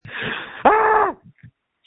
Screams from December 3, 2020
• When you call, we record you making sounds. Hopefully screaming.